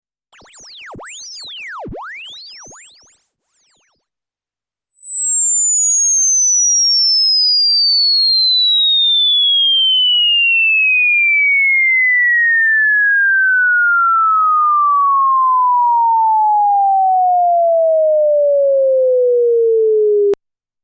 歪む
→ レベルメーターで-9dBぴったりになるように録音したスウィープ信号
可聴域上限の20kHzから400Hzに20秒かけて降りてくるサイン波なので
-9dBでは高い音が変質してキュルキュルというノイズになっちゃう．